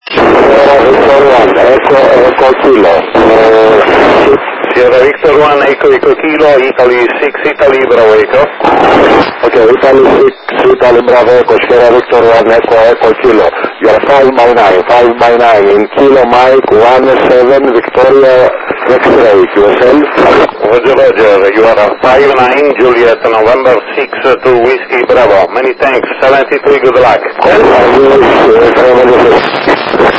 modo operativo VOCE - uplink 437.800 Mhz FM , downlink 145.800 Mhz FM + - Doppler